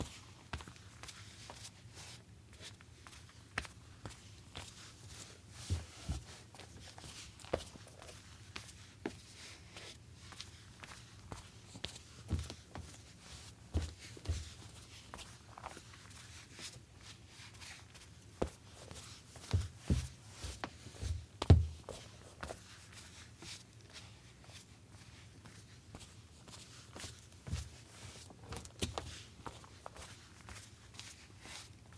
步行紧缩
描述：在干燥的森林里走了几步的声音，以小树枝折断的噼啪声结束。 使用佳能S2 IS数码相机的内部麦克风录制（它本身会引入一些噪音），在Apple Soundtrack Pro中进行了轻微的降噪，并在Mac OS X 10.4中使用SoundConverter转换为FLAC。
标签： 裂纹 紧缩 现场记录 脚步声 步骤
声道立体声